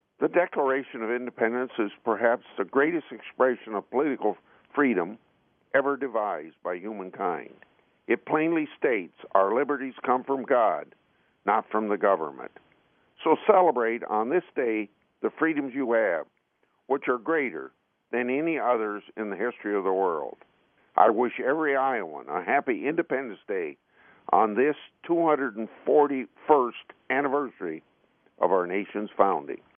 Fourth of July Statement 2017 (Short)